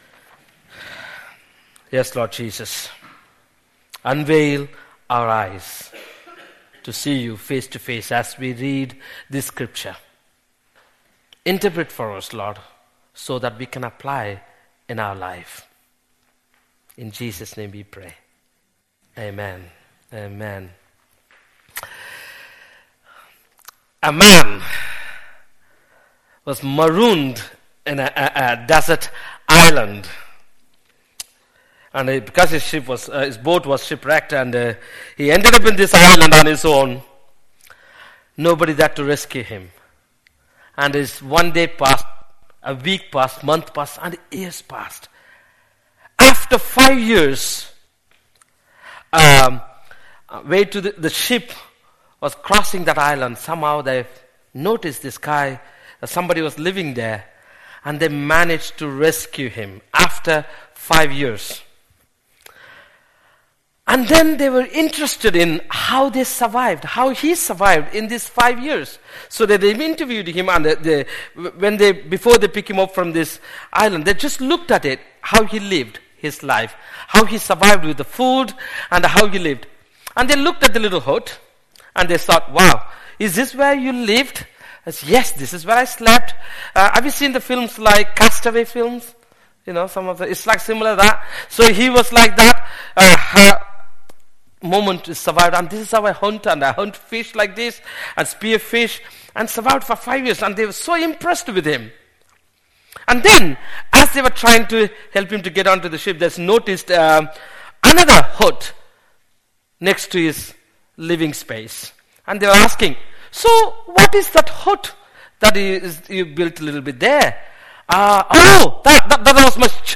An audio version of the sermon is also available.
09-29-sermon.mp3